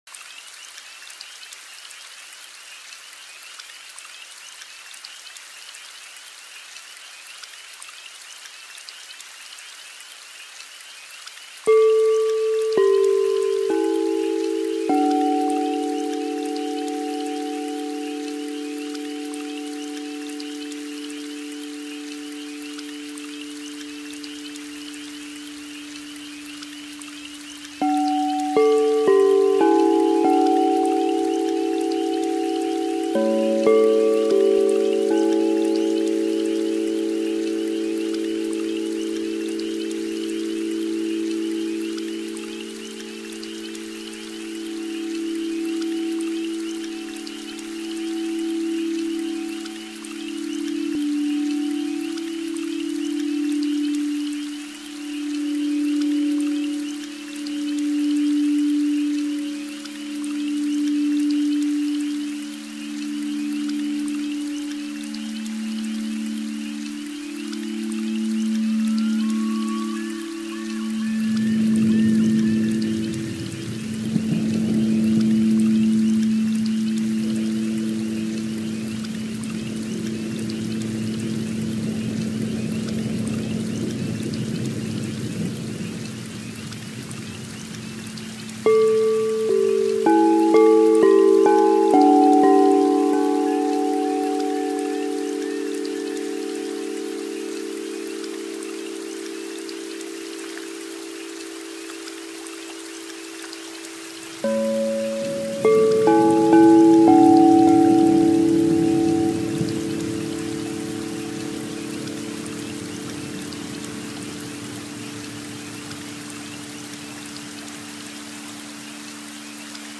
Ce chant tibétain accompagé d’une pluie intense
2020 CHANTS TIBÉTAINS audio closed https
bol-tibetain-pluie-intense.mp3